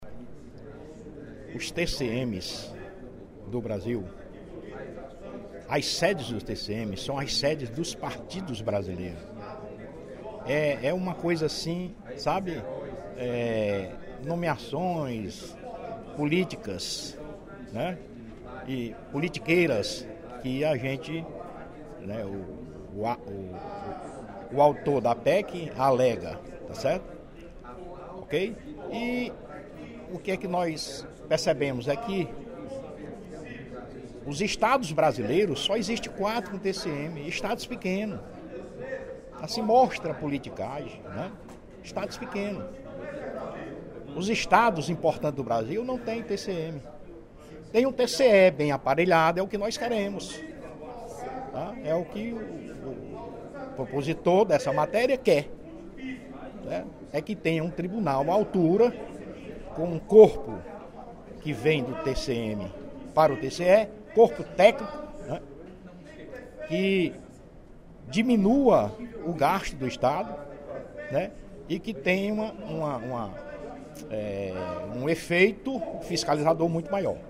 O deputado Mário Hélio (PDT) afirmou, nesta terça-feira (11/07), durante o primeiro expediente da sessão plenária da Assembleia Legislativa, que foi convencido pelos argumentos do autor da proposta de emenda constitucional (PEC) para extinção do Tribunal de Contas dos Municípios (TCM), deputado Heitor Férrer (PSB).